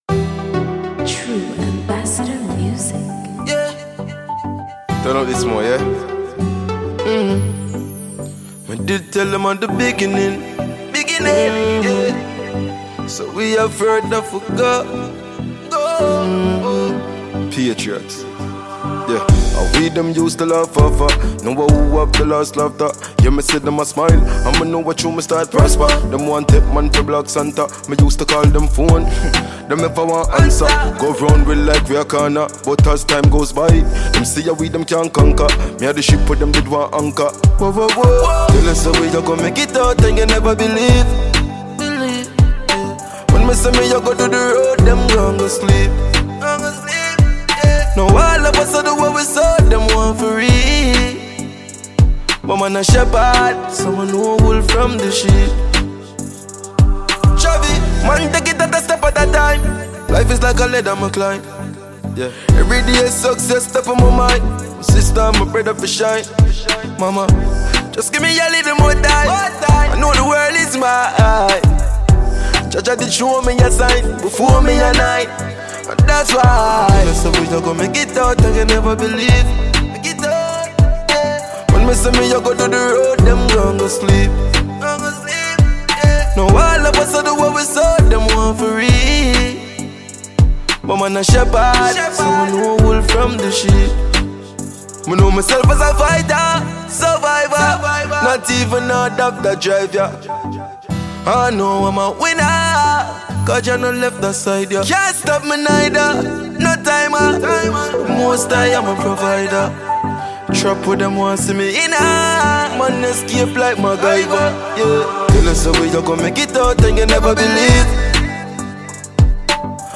Dancehall/HiphopMusic
dancehall